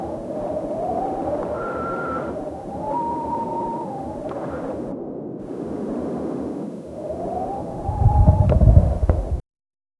Royalty-free cliff sound effects
A man screams 'ohh fuck' from panic before he falls s cliff 0:03 1 loud booming crack from a cliff face about to fall 0:21 Roaring wind intensifying, rushing violently past ears; muffled flapping of loose clothing snapping in the air; echoing clinks of falling carabiners bouncing against the cliff; distant cries of birds scattering. 0:10
roaring-wind-intensifying-ru2cfozt.wav